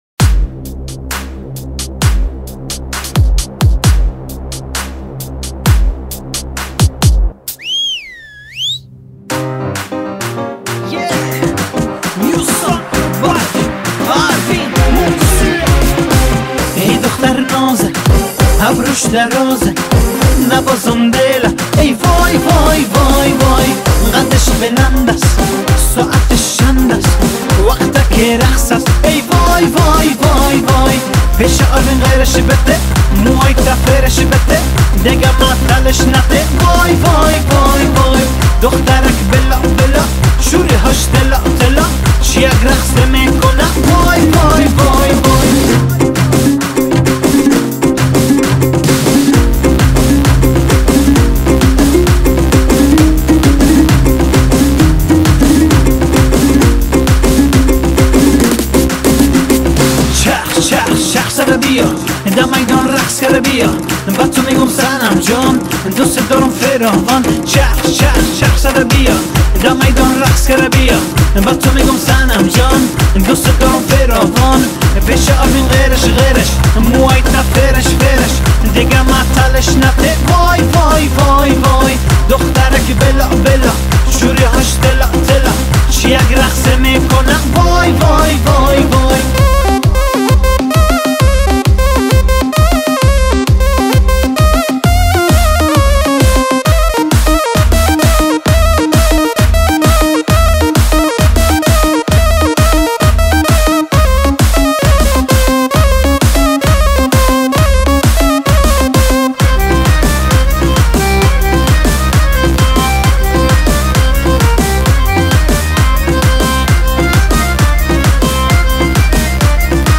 Mahalli